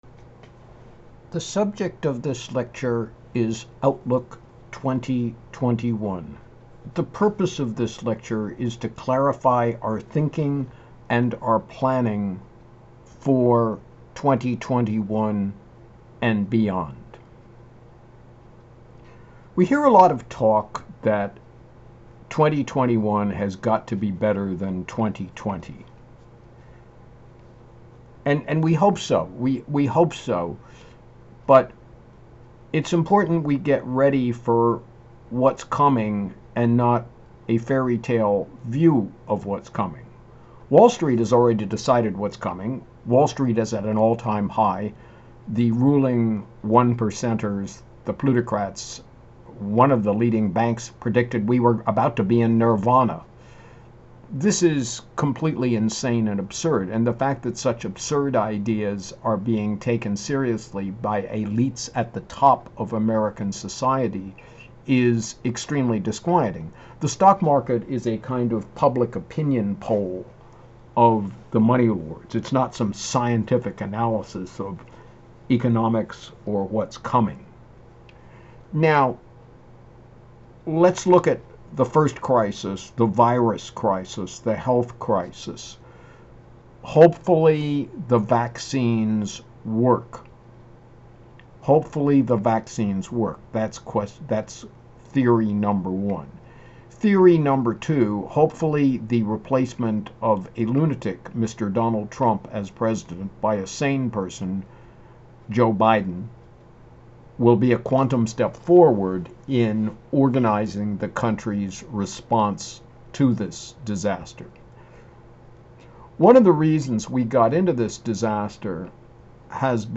REFORM IS POSSIBLE BUT WE NEED TO UNDERSTAND THE SEVERITY OF THE SOLUTIONS THAT ARE NEEDED. LISTEN TO LECTURE